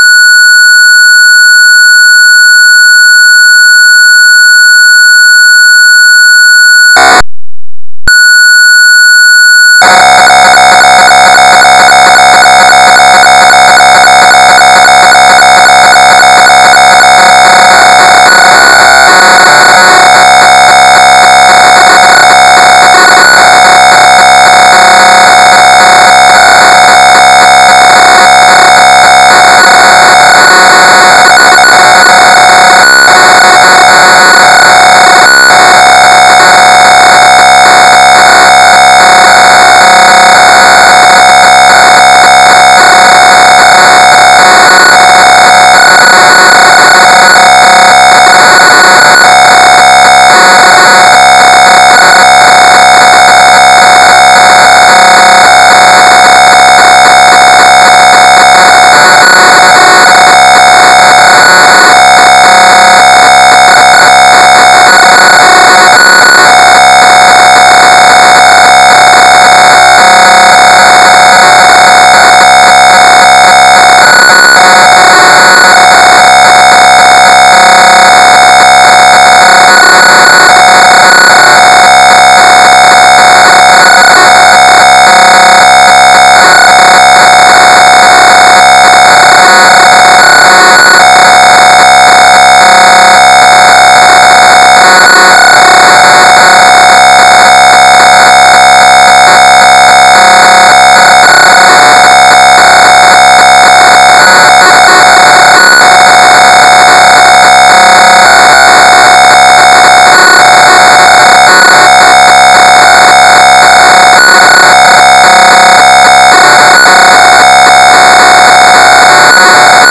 ПК-01 Львов WEB Tape Loader